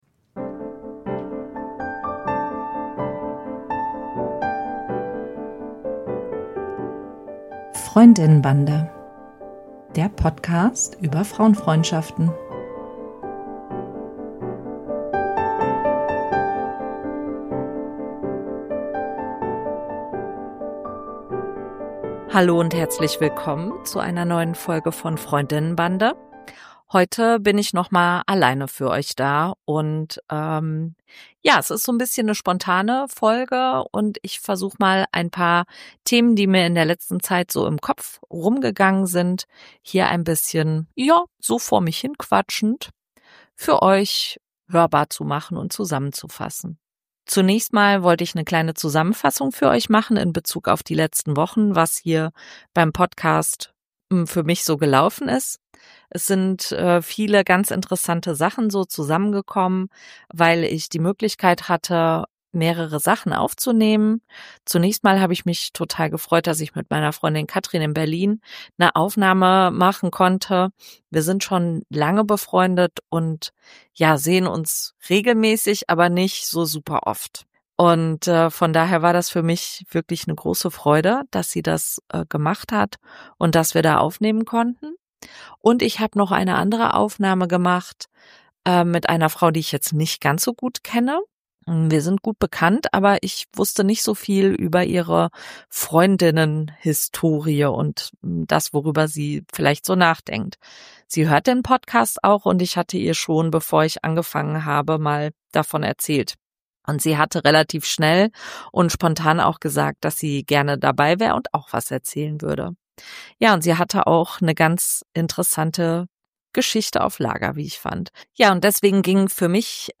Ich bin alleine für euch da, denke laut, erzähle, was passiert ist und passieren wird in Bezug auf den Podcast!